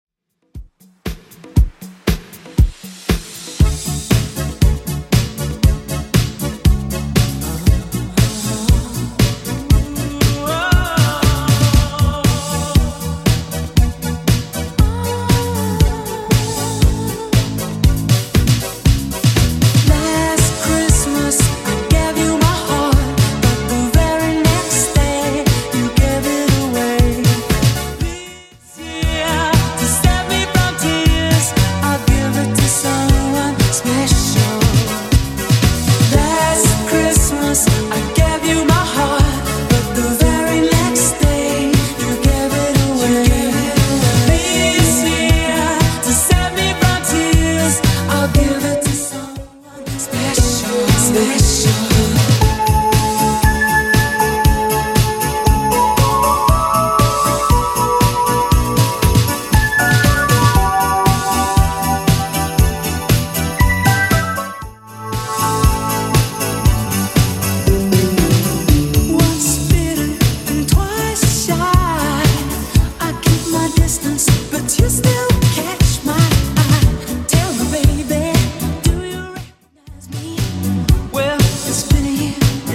BPM: 118 Time